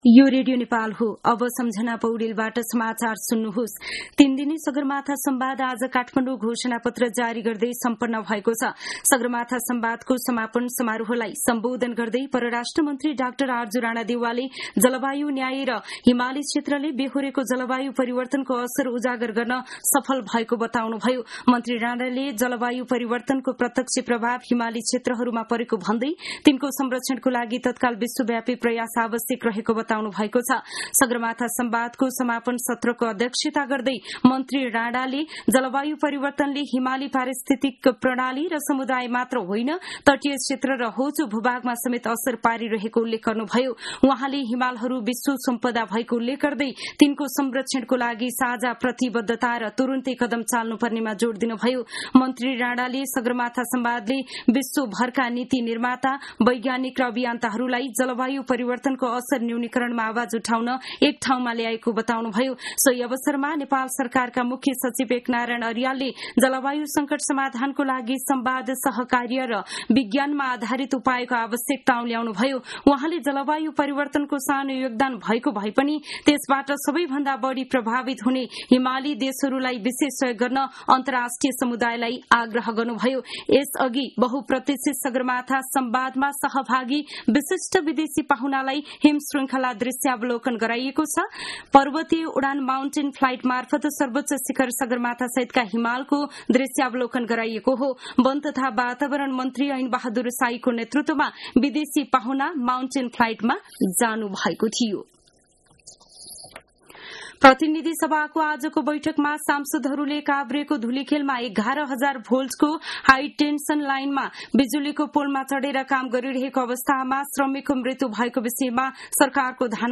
दिउँसो १ बजेको नेपाली समाचार : ४ जेठ , २०८२
1-pm-news-1-4.mp3